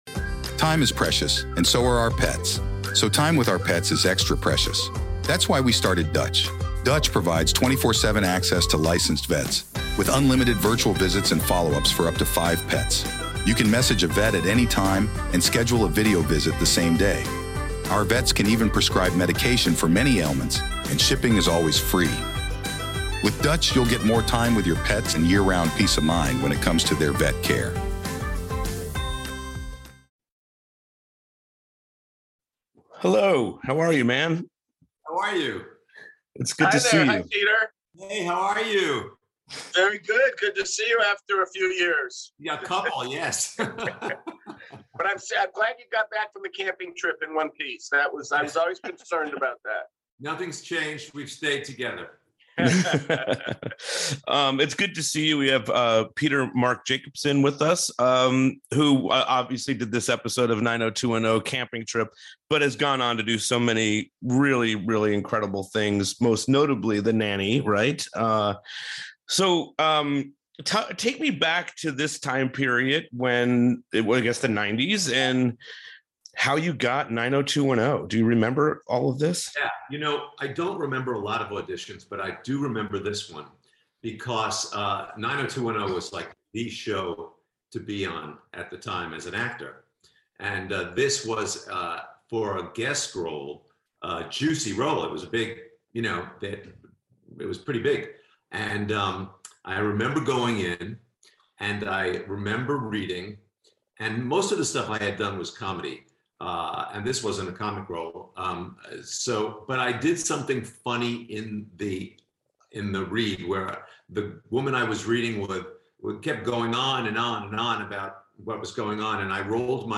Bonus Interview